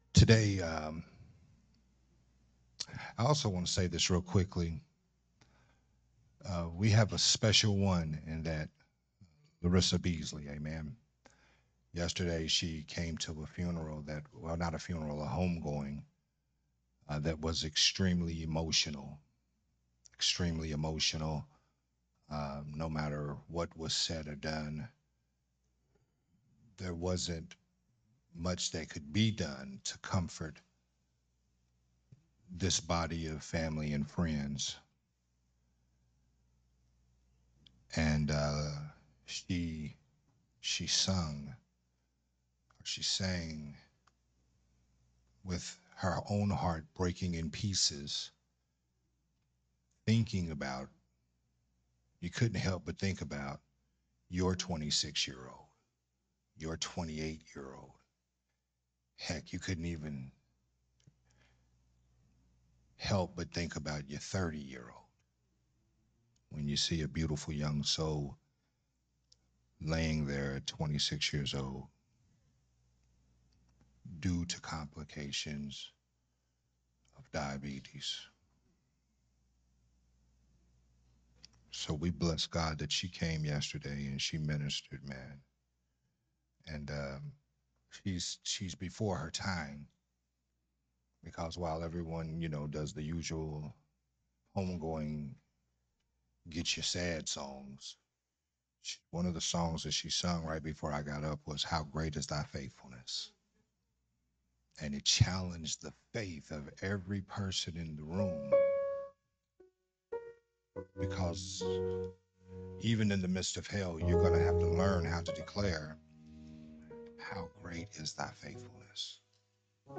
recorded at Unity Worship Center on November 19th